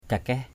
/ka-kɛh/